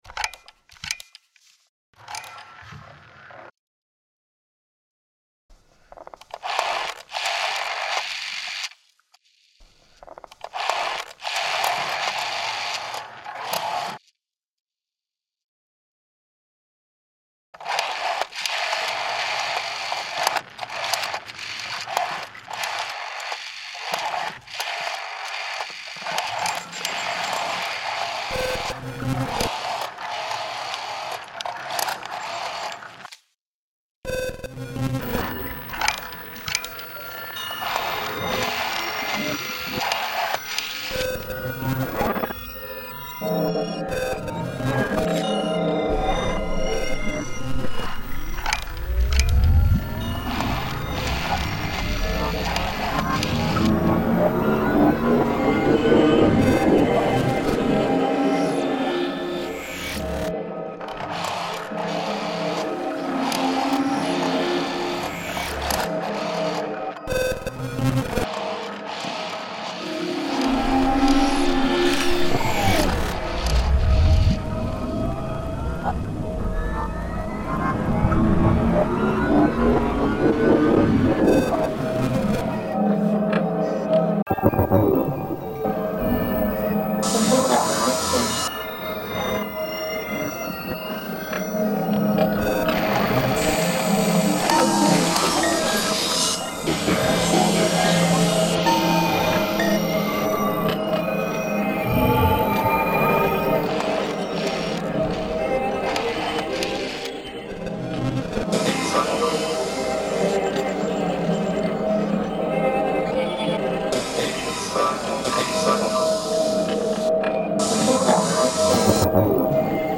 This is part of the Obsolete Sounds project , the world’s biggest collection of disappearing sounds and sounds that have become extinct – remixed and reimagined to create a brand new form of listening.